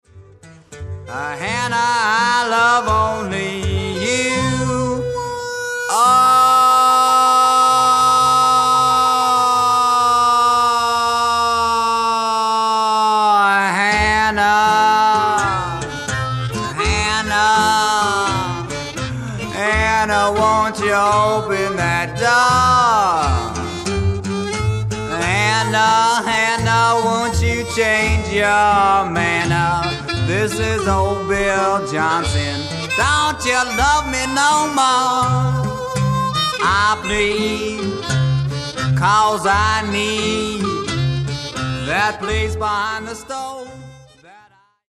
JUG MUSIC / AMERICAN ROOTS MUSIC / BLUES